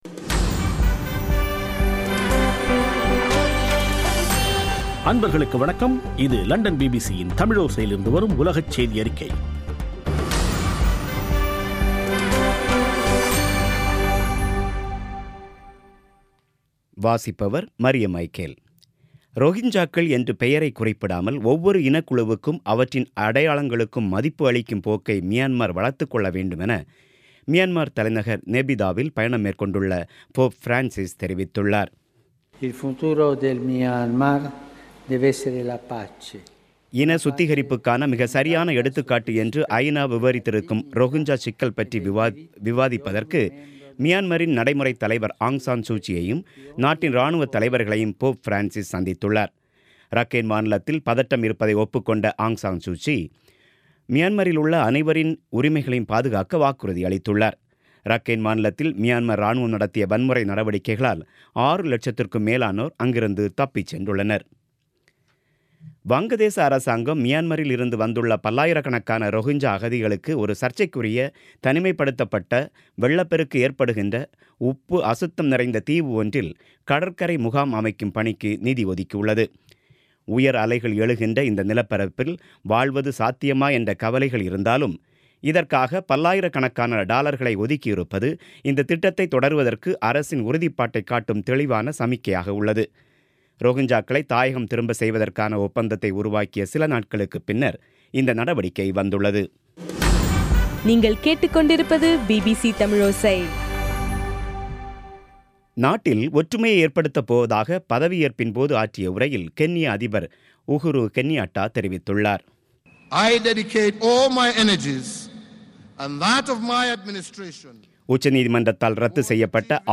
பிபிசி தமிழோசை செய்தியறிக்கை (28/11/2017)